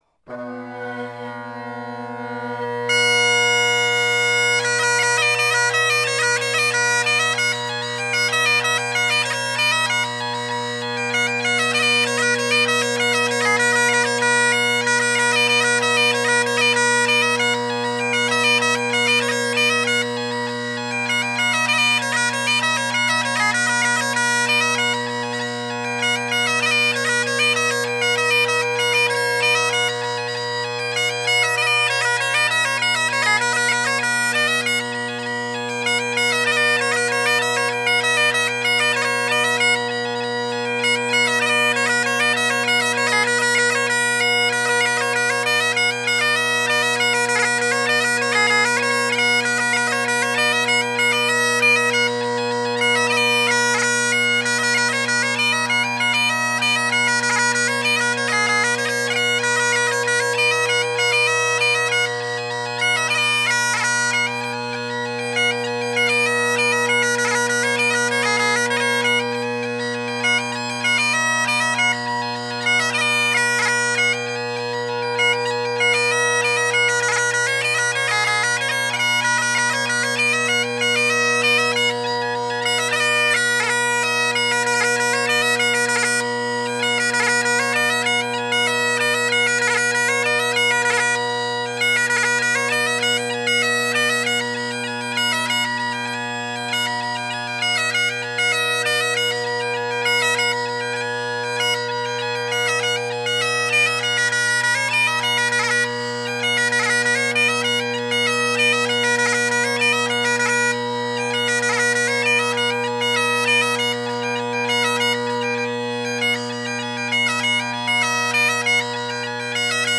Drone Sounds of the GHB, Great Highland Bagpipe Solo, Reviews
My birls sucked more but I didn’t have the big flub half-way through Fleshmarket Close.
hendersons_rocket-tenors_canning-polycarb-bass_colin-kyo_gilmour.mp3